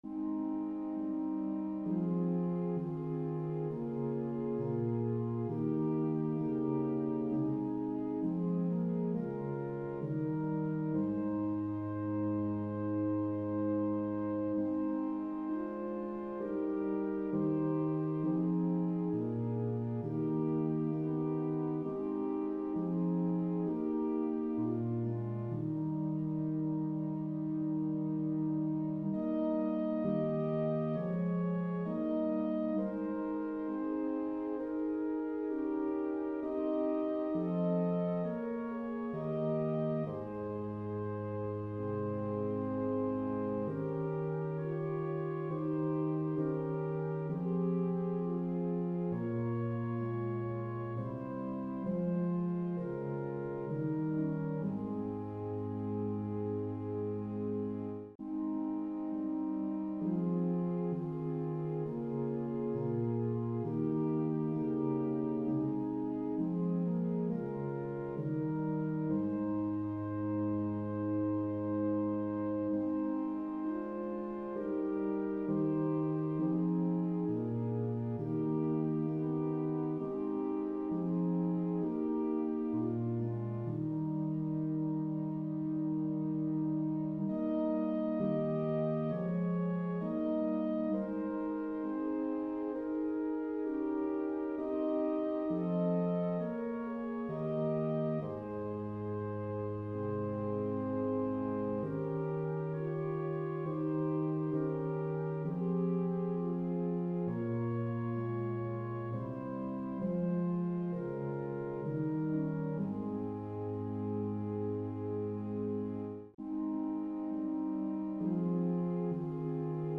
Click the Button to sing the prayer in Ab, or play the song in a New Window
Soul-Of-My-Saviour-Maher-Ab.mp3